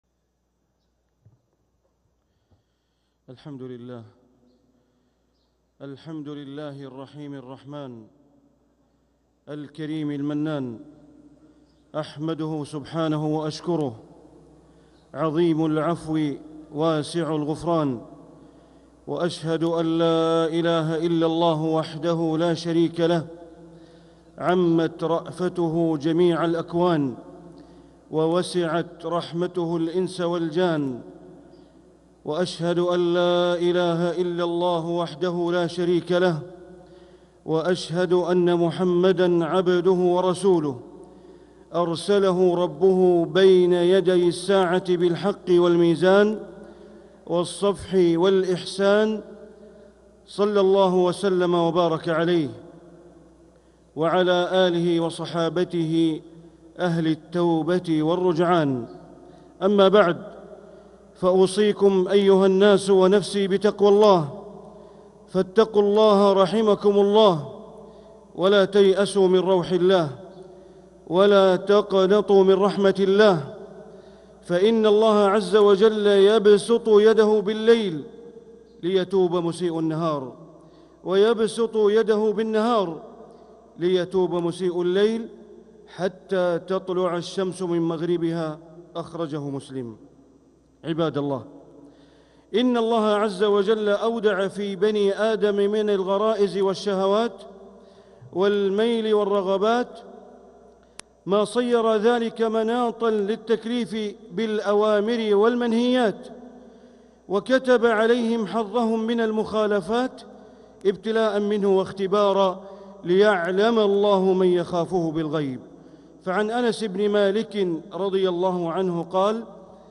خطبة الجمعة 16 محرم 1447هـ > خطب الشيخ بندر بليلة من الحرم المكي > المزيد - تلاوات بندر بليلة